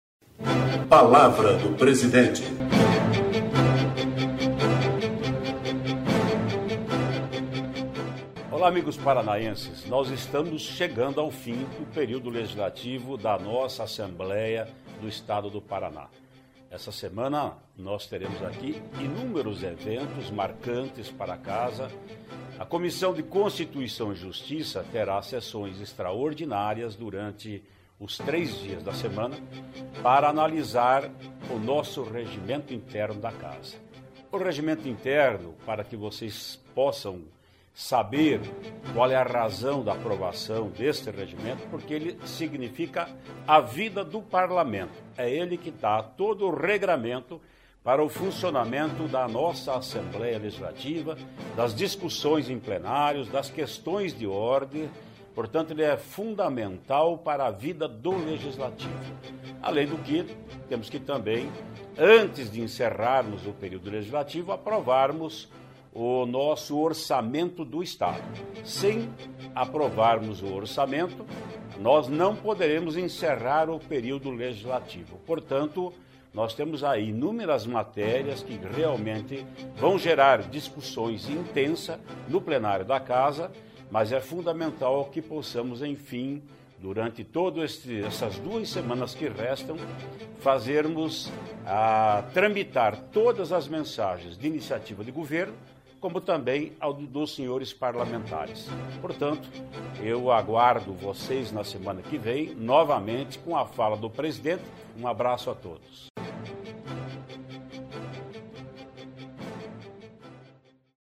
Ouça o que o presidente  da Assembleia, deputado Ademar Traiano (PSDB) diz na Palavra do Presidente desta semana.